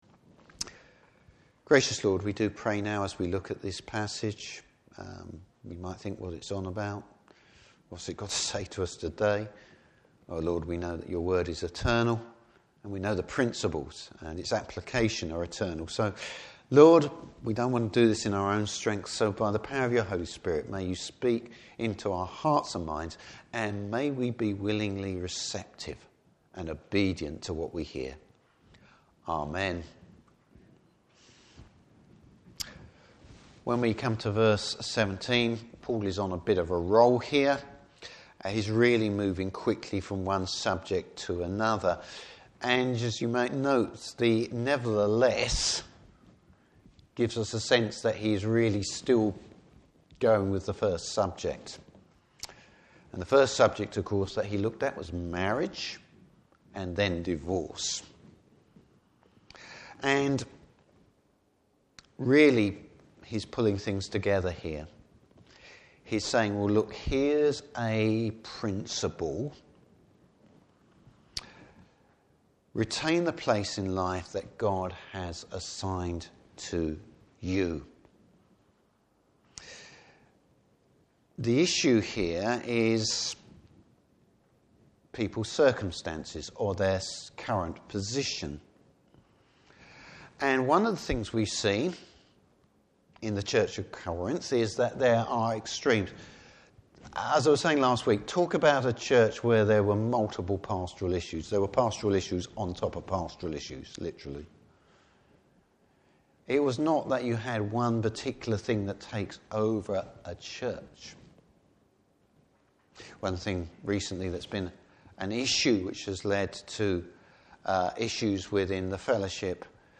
Service Type: Morning Service Serving the Lord, whatever one’s circumstances.